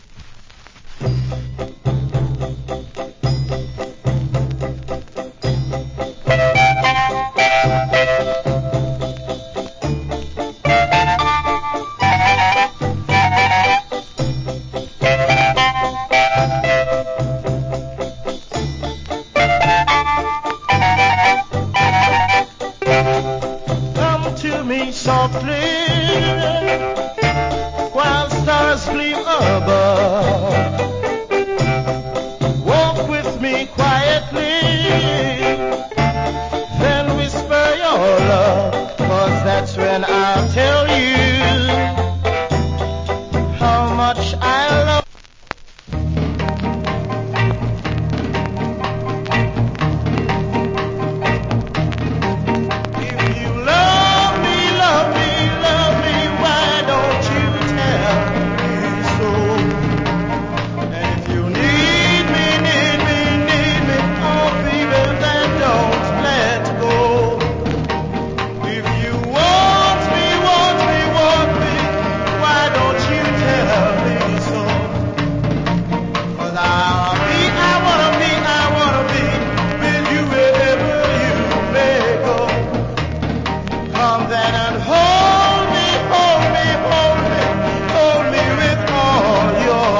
Great Ballad Vocal.